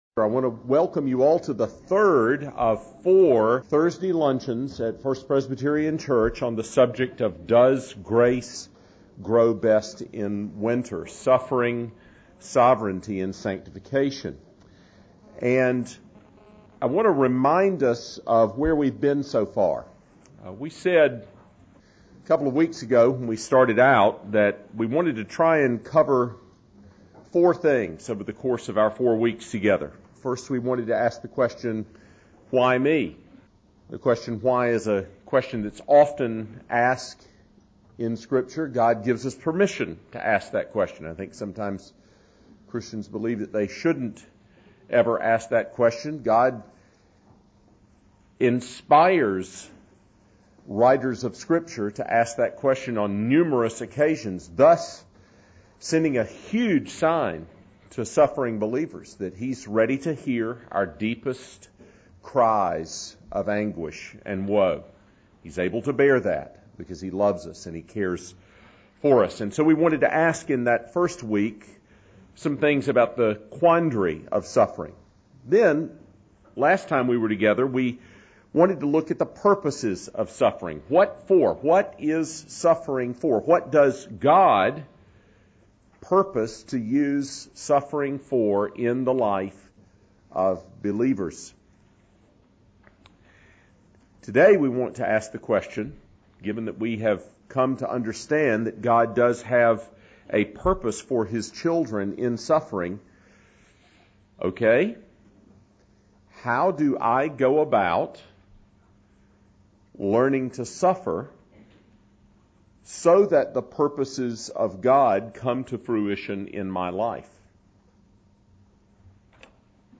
Suffering…Sovereignty…and Sanctification Winter Luncheon Series “HOW SO?”